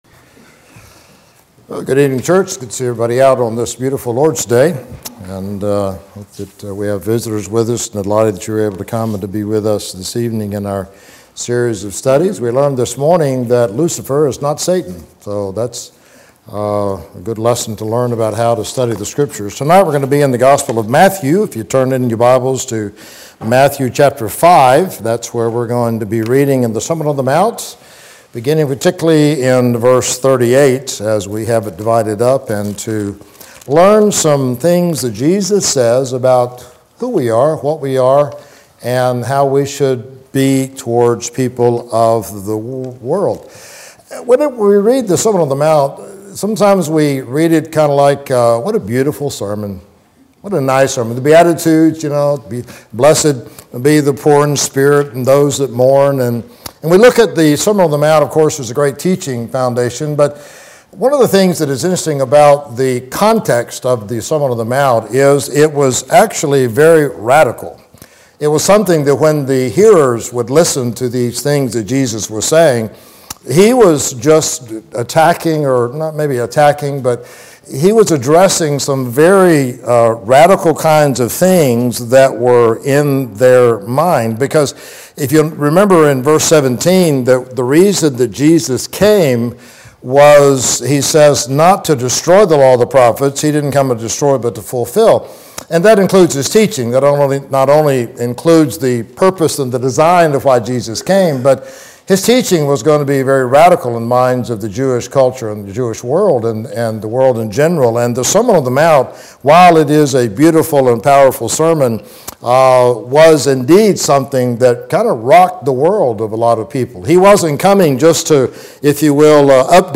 Sun PM – Sermon